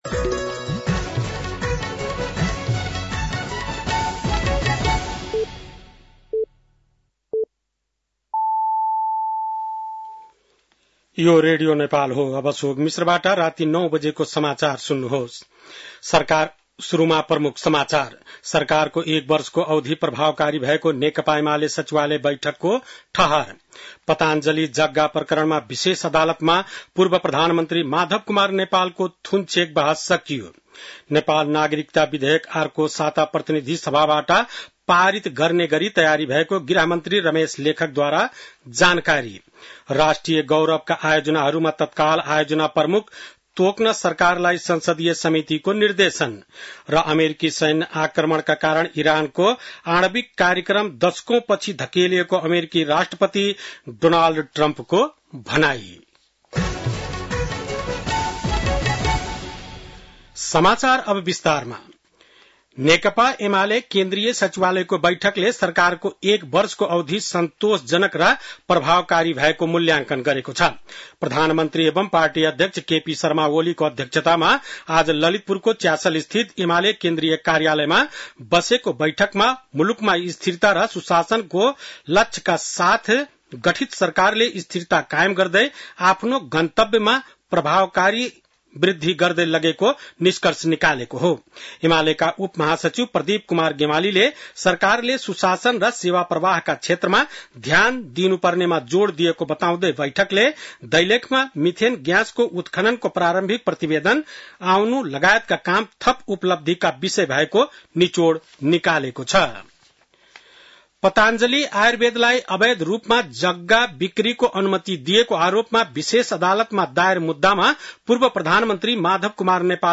बेलुकी ९ बजेको नेपाली समाचार : ११ असार , २०८२
9-pm-nepali-news-3-11.mp3